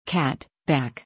無声音 CAT/kæt/, BACK/bæk/
cat-1.mp3